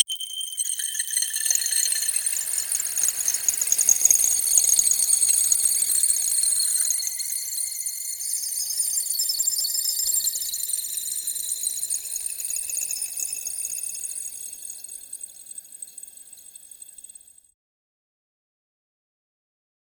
A sharp, crystalline crackle of forming ice, like frost rapidly spreading over glass. Starts with tiny, high-pitched icy pops and snaps, followed by layered crackling fractures. Subtle shimmering chimes and a cold, airy hiss add a magical feel. The sound feels crisp, brittle, and powerful, with light echo and sparkling frost energy pulsing through each crack. Cold, mystical, and enchanted.
a-sharp-crystalline-crack-4s4i2knu.wav